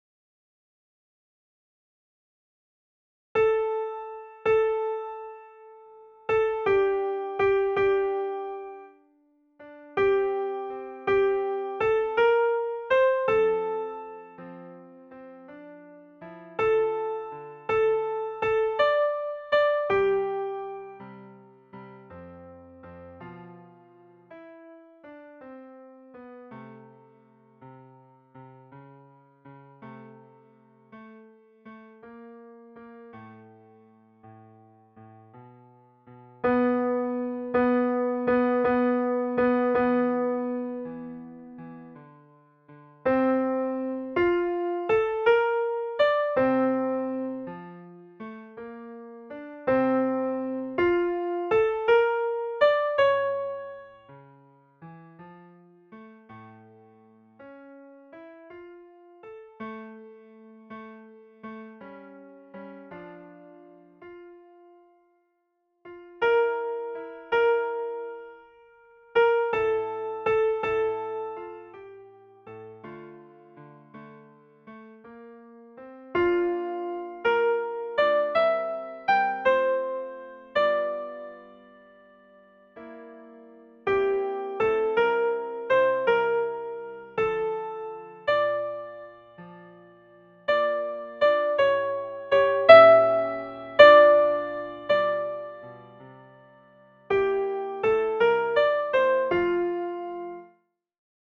CHOEUR ET ECHO
Soprani
le_vent_dans_la_foret_soprani.mp3